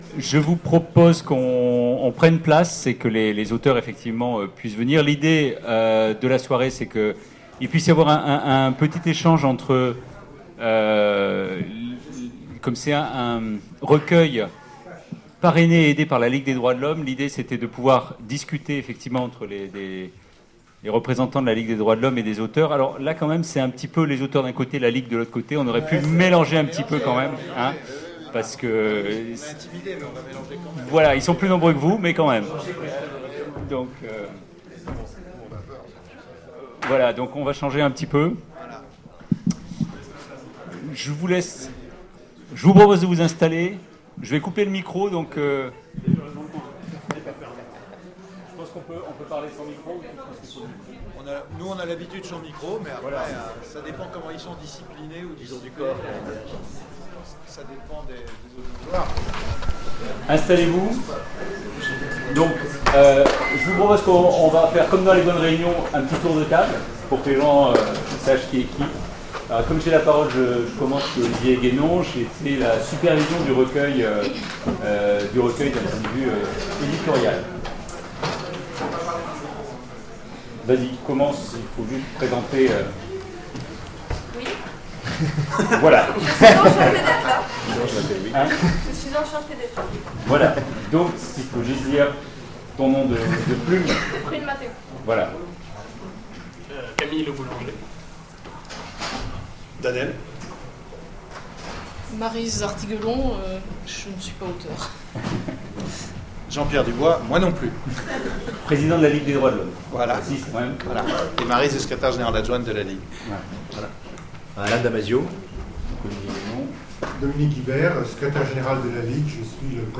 Voici l'enregistrement de la conférence qui a eu lieu pour le lancement.